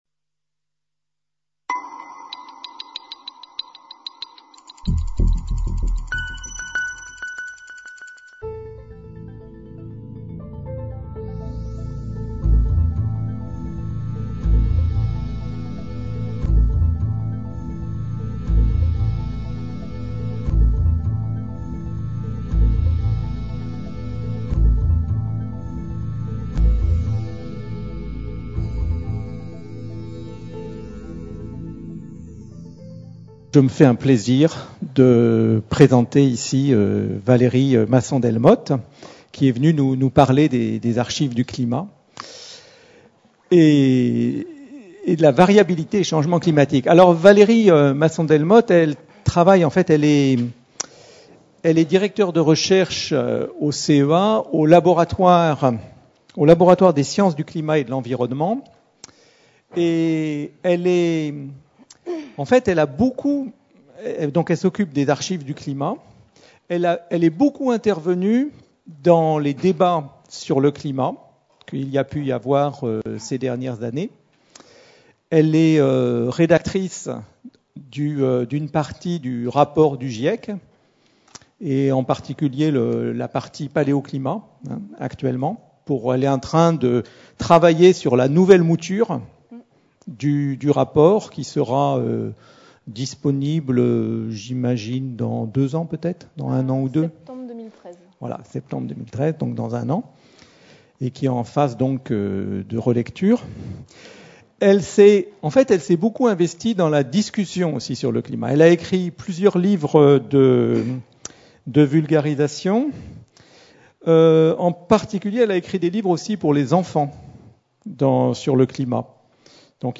Conférence de l'Institut d'Astrophysique de Paris du mardi 6 novembre 2012 donnée par Valérie Masson-Delmotte (paléoclimatologue, directeur de recherche au CEA - Institut Pierre Simon Laplace, Laboratoire des Sciences du Climat et de l'Environnement).